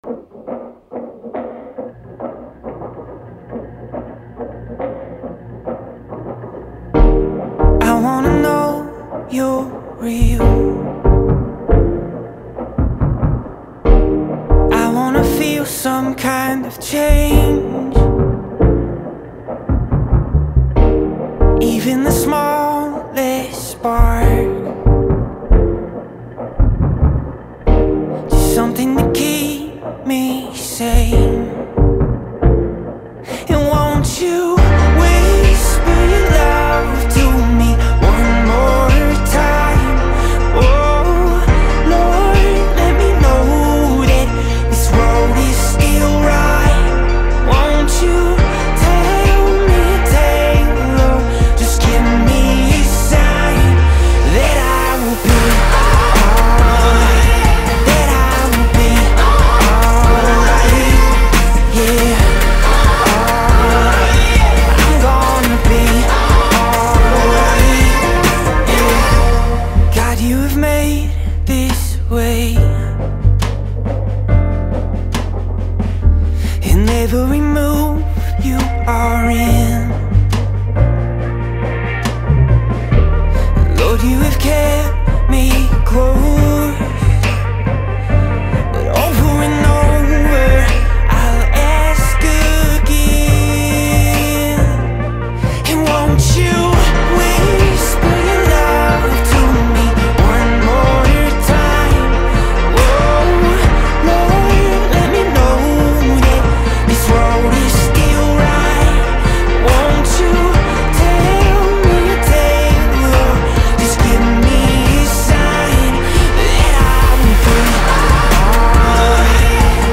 589 просмотров 389 прослушиваний 34 скачивания BPM: 139